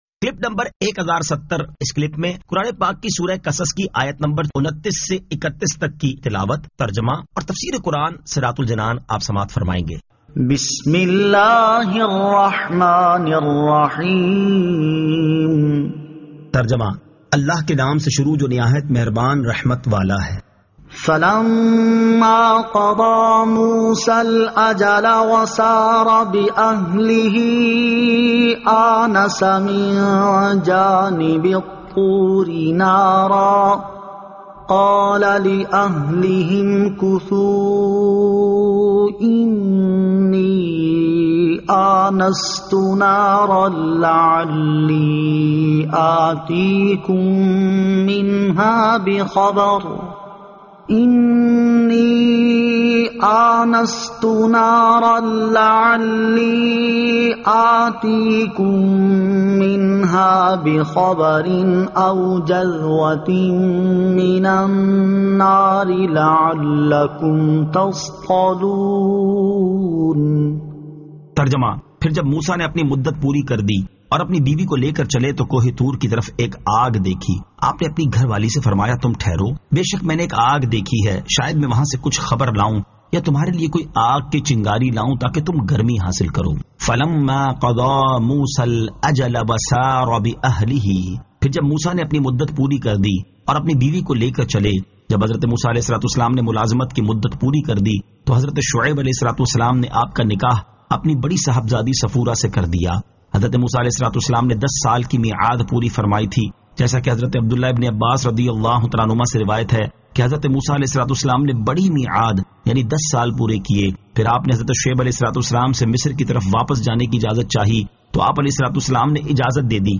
Surah Al-Qasas 29 To 31 Tilawat , Tarjama , Tafseer